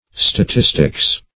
Statistics \Sta*tis"tics\ (st[.a]*t[i^]s"t[i^]ks), n. [Cf. F.